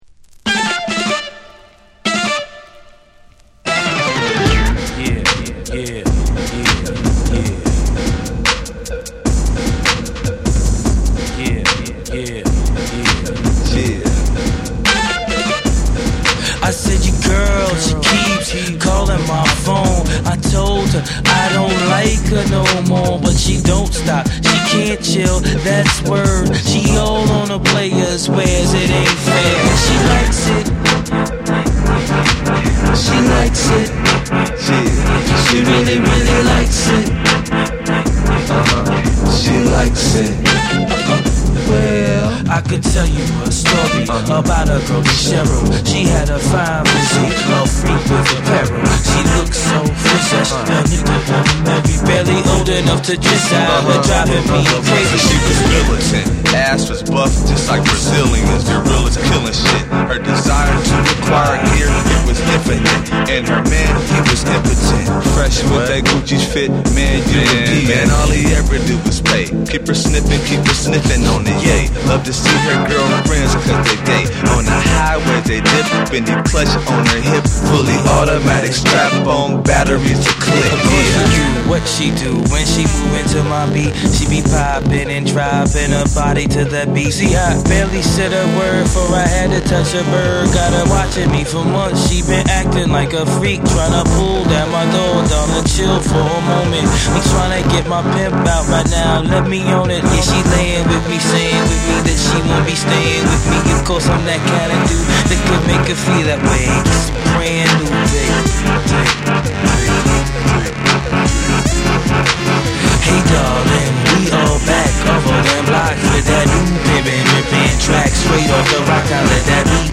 グリッチ感のあるビートメイクとジャジーで遊び心にあふれたメロディが絡み合う、独特の世界観が光る作品。
BREAKBEATS